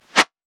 weapon_bullet_flyby_06.wav